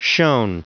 Prononciation du mot shone en anglais (fichier audio)
Prononciation du mot : shone